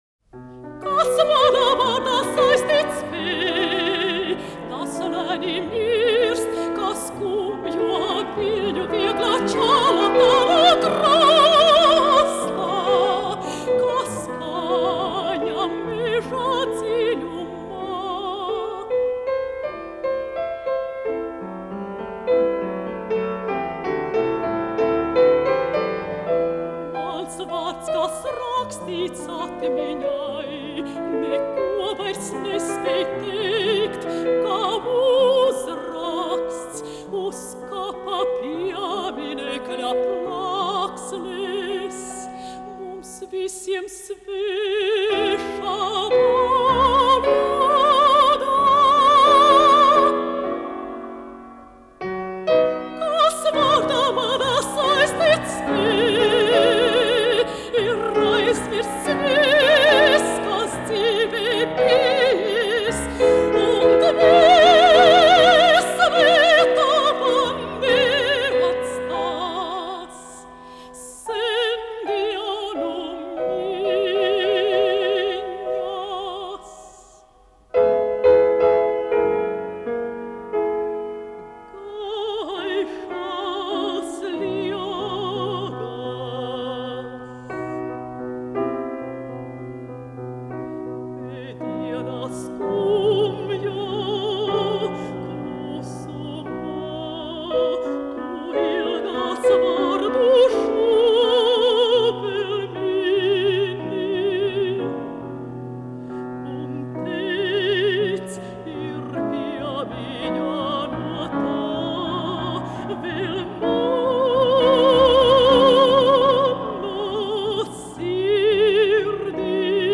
Audiokasete
Mūzikas ieraksts